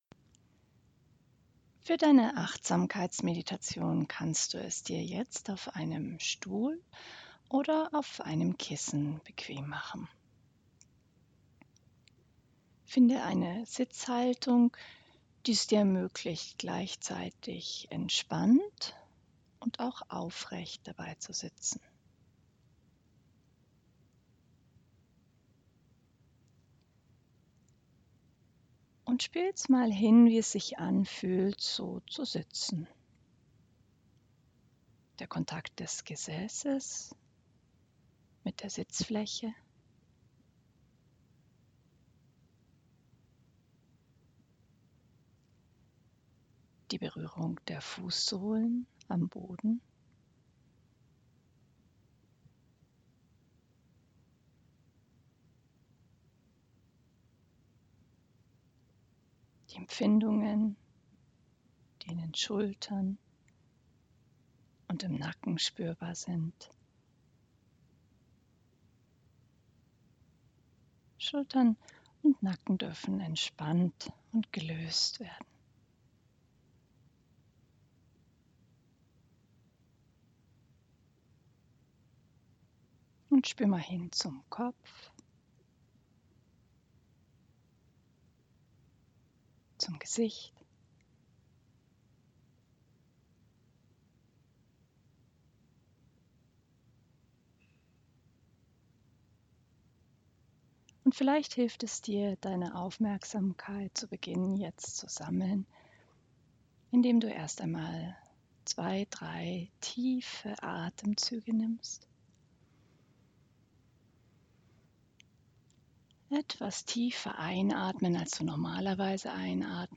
Resources MINDFULNESS & COMPASSION - MIND- & SKILLSET GUIDED MEDITATIONS (in German) Dieser Audiokurs ist ein leichter Einstieg in die Achtsamkeitsmeditation.